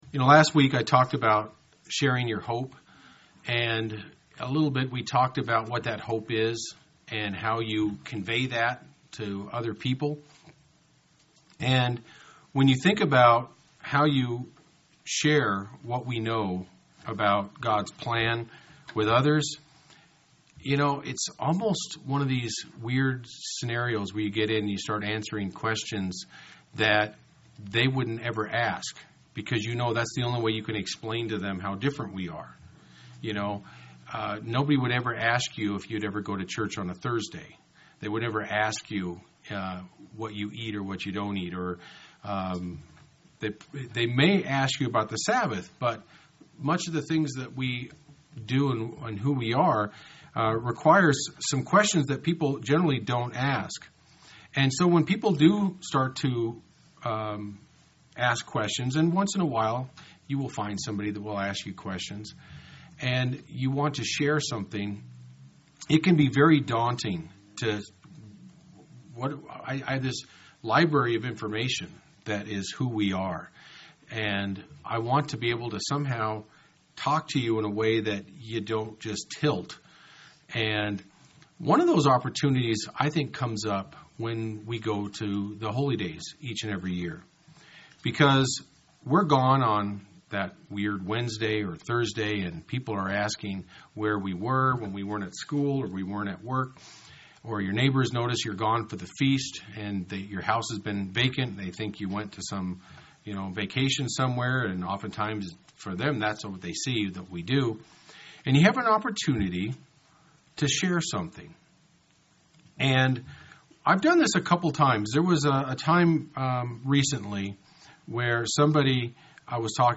UCG Sermon Notes When you think about your faith you might quickly go to things that you do that might seem different or odd to others.